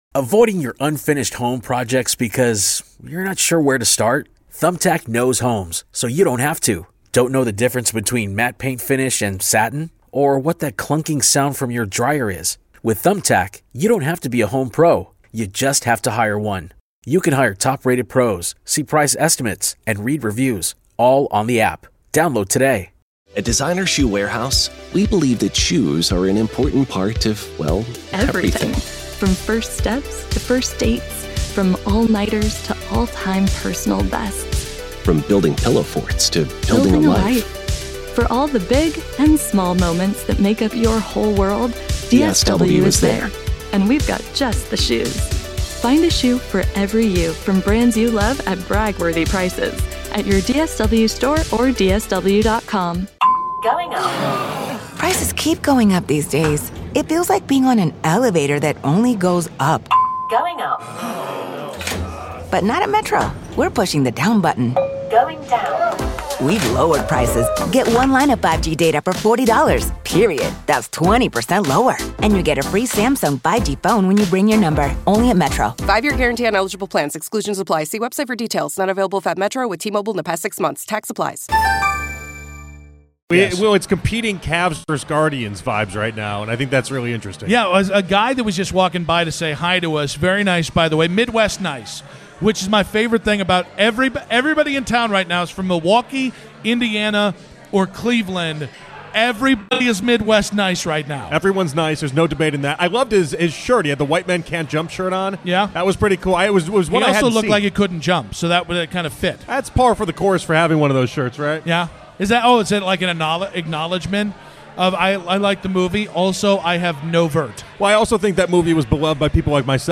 Monday Morning Quarterback author Albert Breer joins Afternoon Drive to break down what the Browns are doing at quarterback and the state of the rest of the roster before summer training sessions.